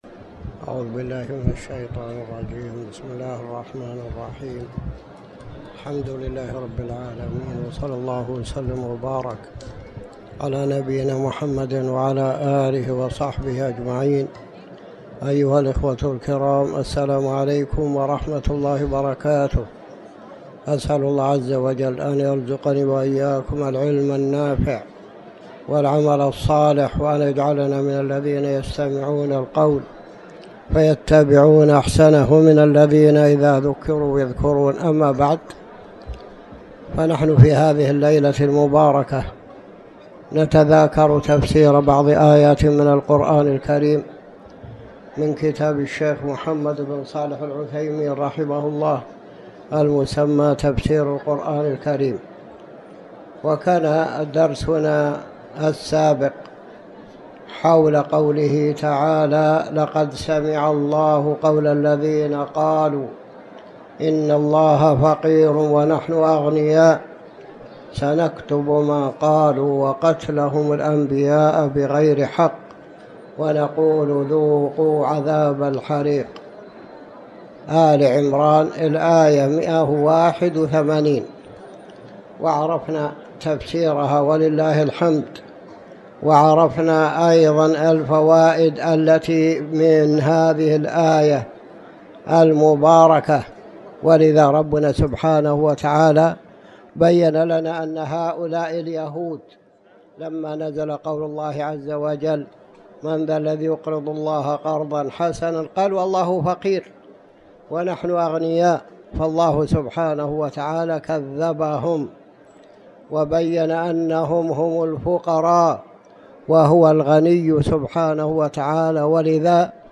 تاريخ النشر ٩ رجب ١٤٤٠ هـ المكان: المسجد الحرام الشيخ